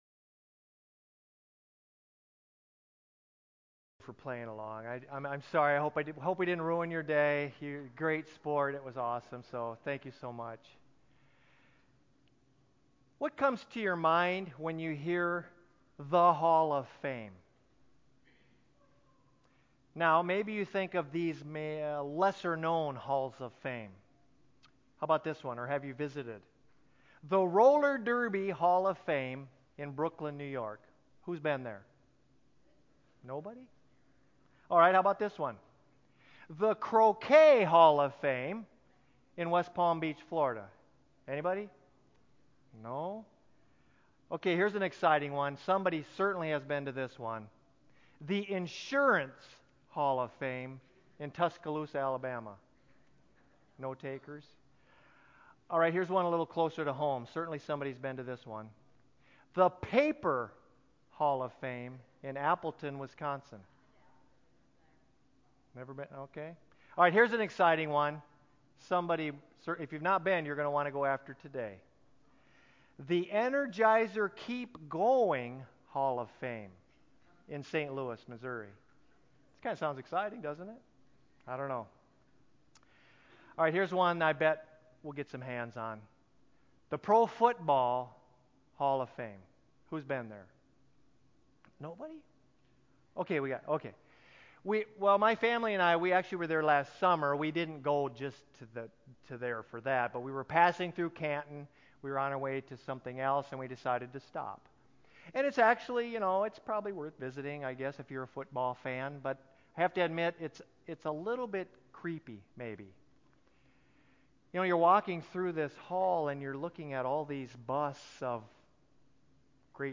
church-sermon8.11.19-CD.mp3